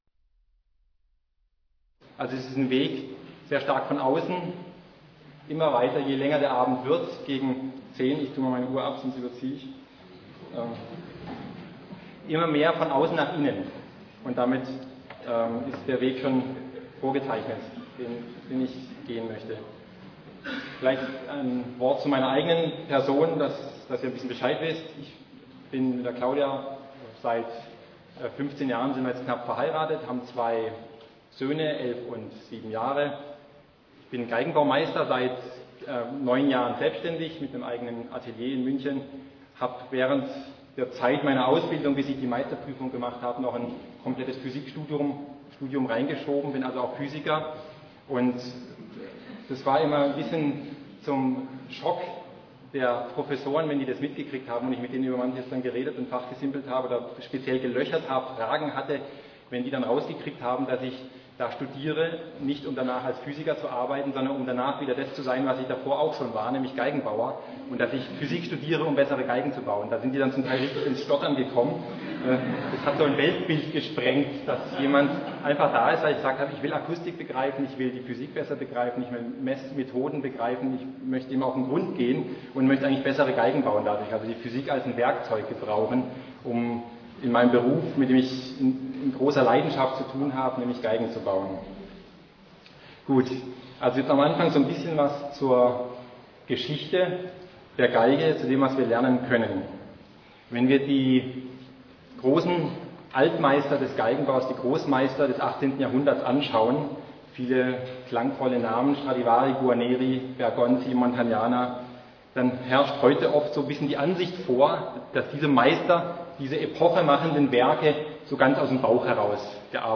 Die Geige – eine Vision Vortrag